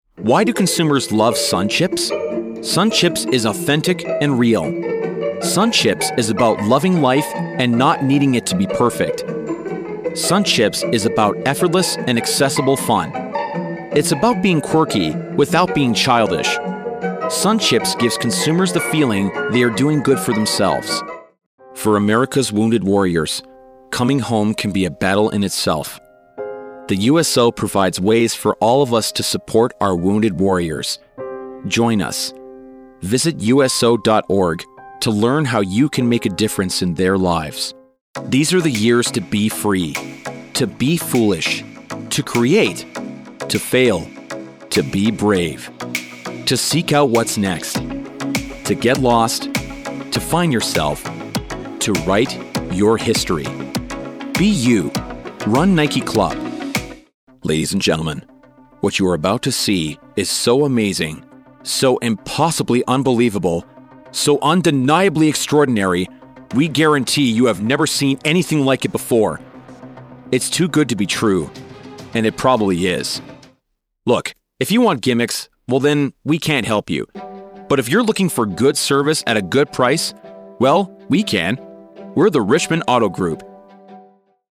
0916Commercial_Demo.mp3